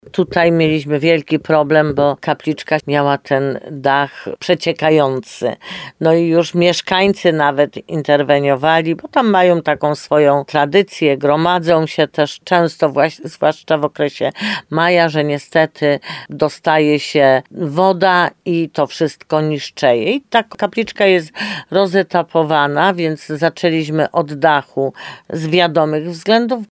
Remont kapliczki jest podzielony na etapy, więc zaczęliśmy od dachu z wiadomych względów – mówi burmistrz Jolanta Juszkiewicz. W planie jest też wykonanie odwodnienia i remont murów kapliczki.